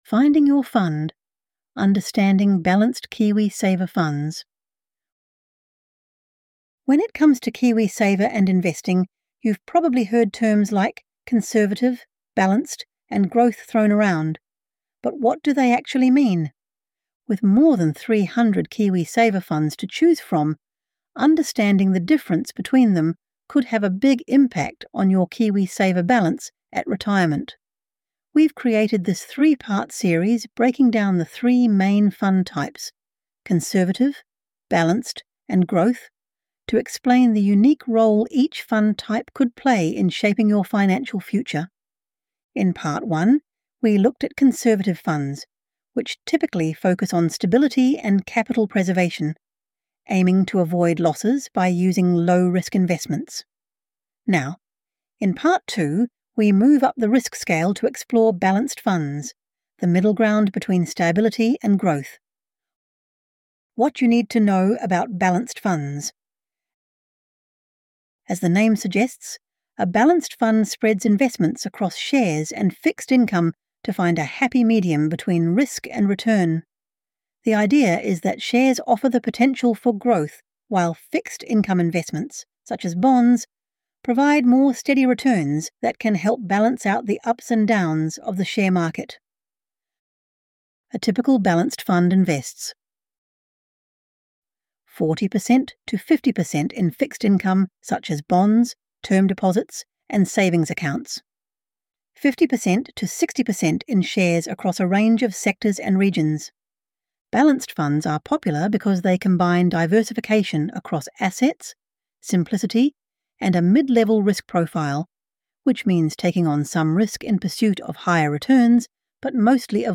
This content features an AI-generated voice for narration purposes.
ElevenLabs_Kiwisaver_-_Finding_your_fund_Understanding_Balanced_KiwiSaver_Funds.mp3